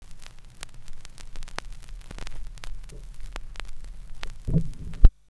VINYLSTOP -L.wav